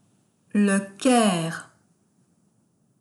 b le cœur (the heart) Le Caire (Cairo)
The above errors are due to incorrect pronunciation of the vowel sound [œ] (in the words „soeur” and „coeur”), which doesn’t exist in the students’ mother tongues.
Le-Caire.wav